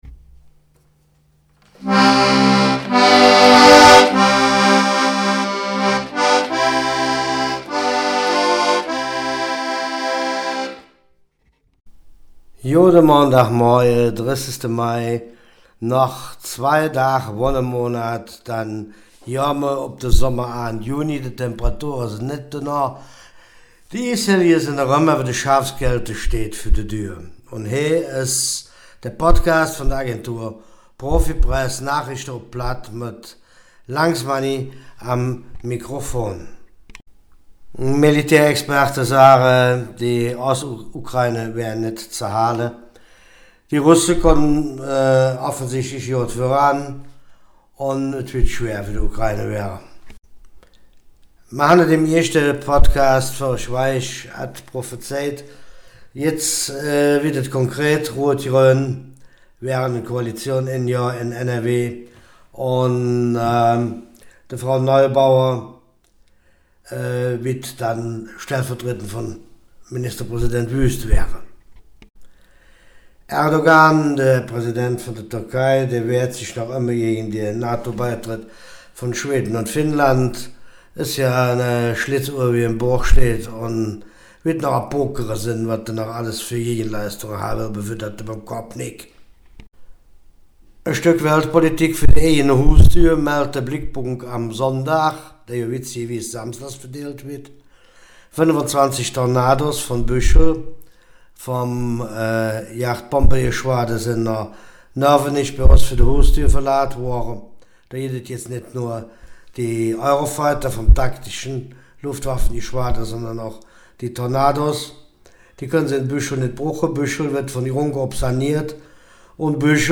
Nachrichten vom 30. Mai
Podcast op Platt Nachrichten vom 30.